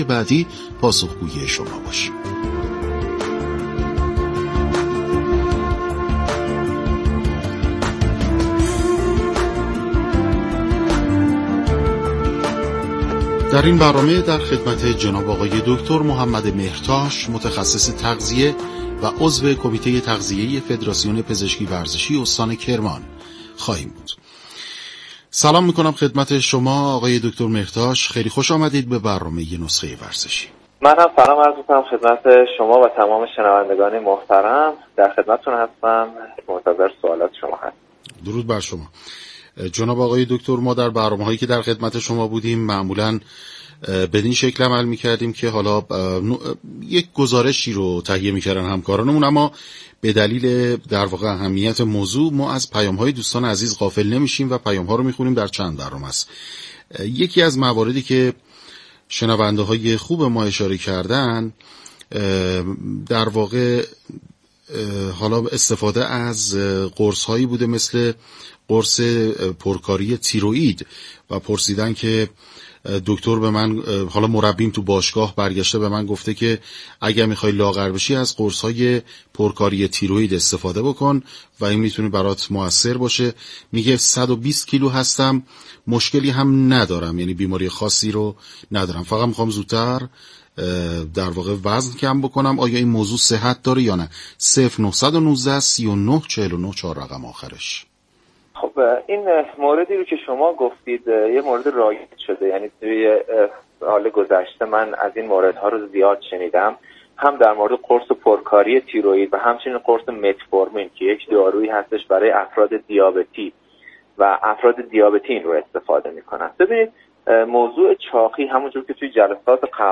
/پزشکی ورزشی و رادیو ورزش/